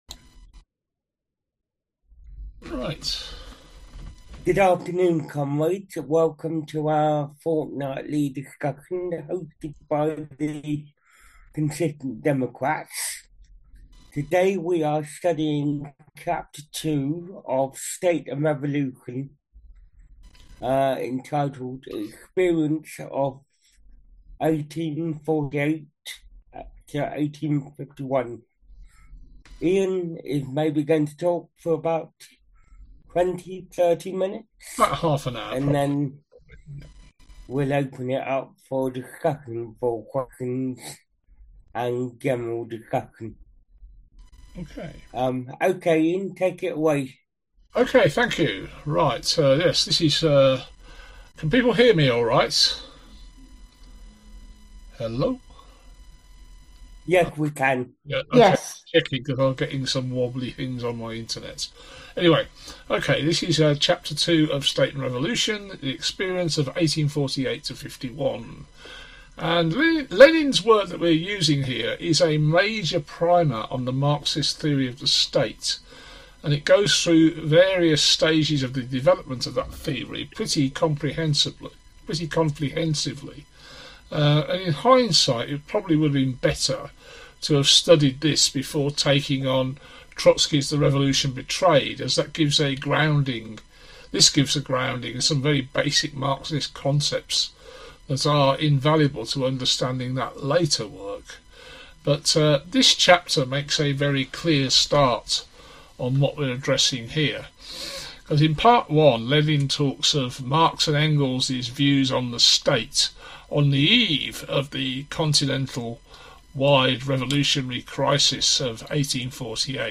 The following is the notes/text of a presentation delivered by a Consistent Democrats speaker on 23rd November on this chapter. The recording of the presentation and discussion is here.